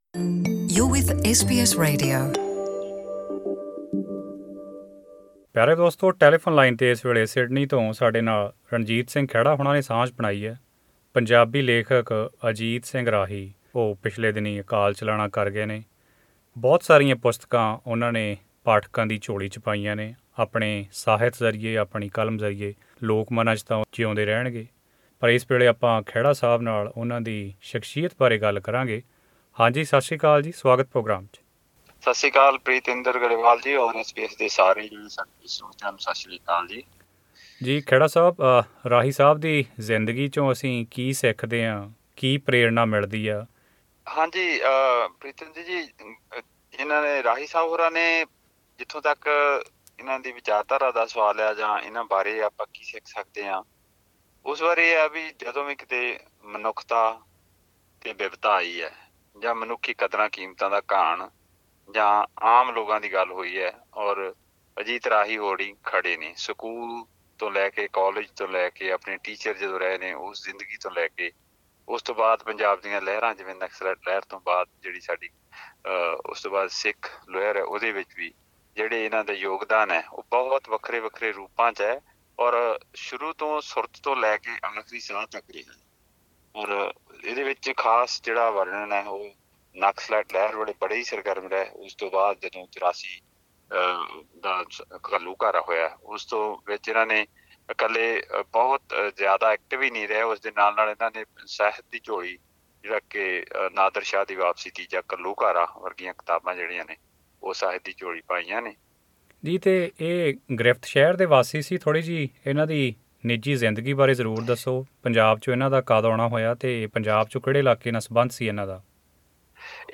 ਪੇਸ਼ ਹੈ ਉਨ੍ਹਾਂ ਦੀ ਸ਼ਖਸ਼ੀਅਤ ਦੇ ਕੁਝ ਅਹਿਮ ਪੱਖ ਉਜਾਗਰ ਕਰਦੀ ਇਹ ਆਡੀਓ ਇੰਟਰਵਿਊ….